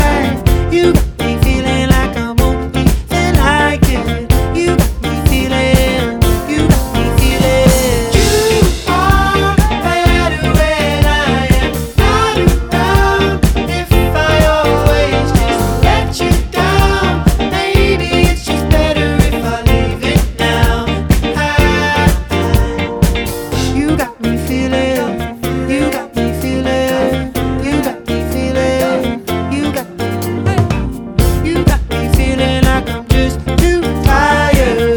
Скачать припев, мелодию нарезки
Жанр: Альтернатива Длительность